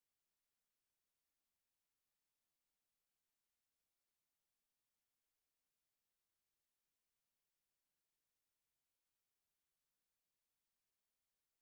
Вот голый шум пульта Вложения nois.wav nois.wav 2,9 MB · Просмотры: 327